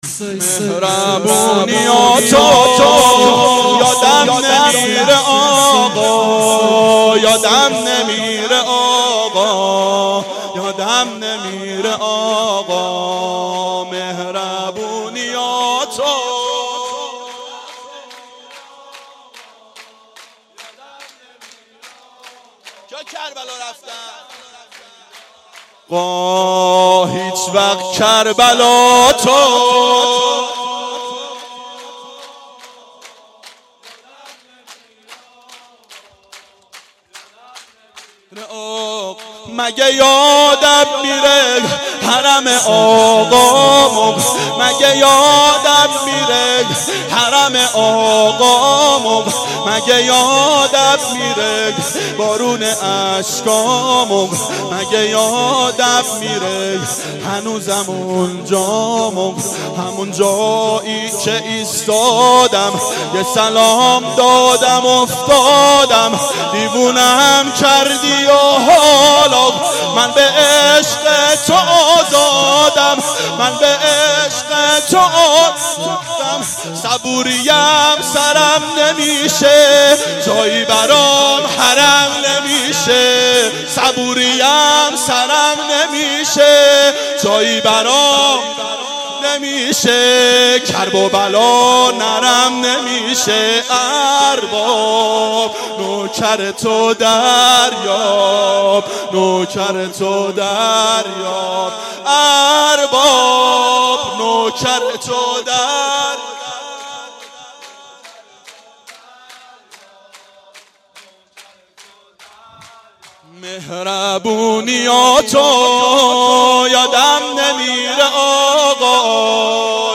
میلاد امام حسین و حضرت اباالفضل علیهماالسلام 93